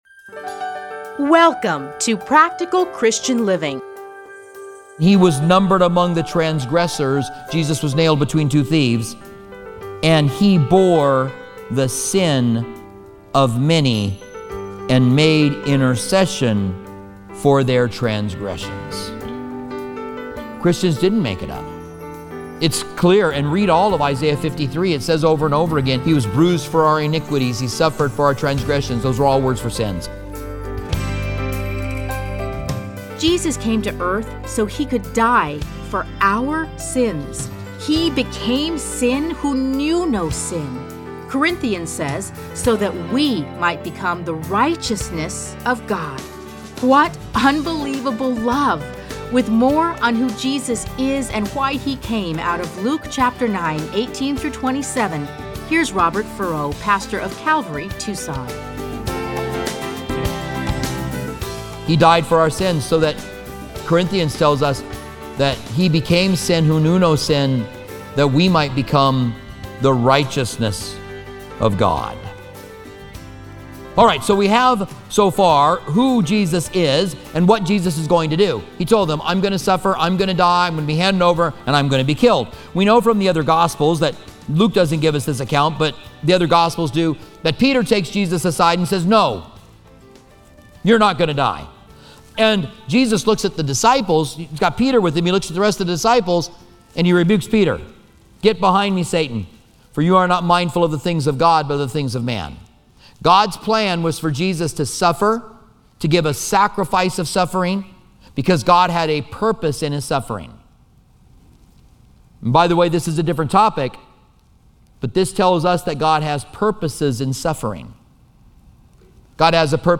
Listen to a teaching from Luke 9:18-27.